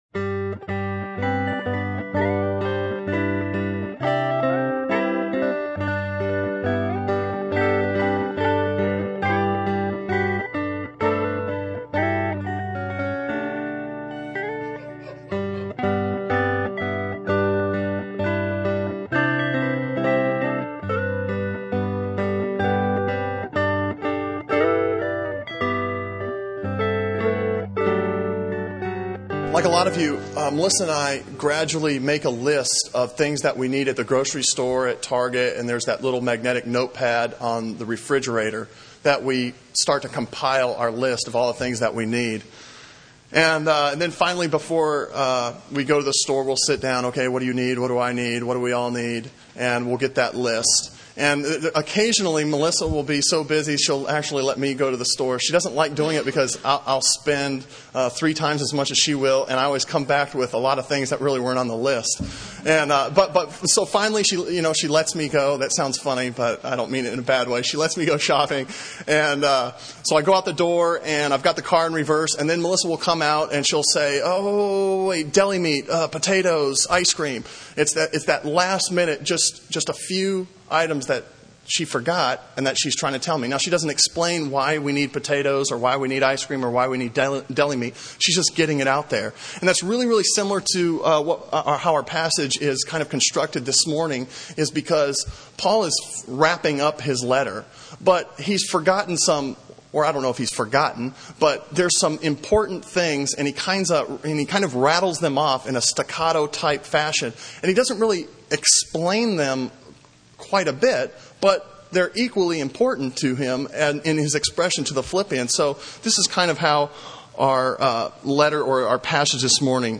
Sermon on Philippians 4:2-9 from November 12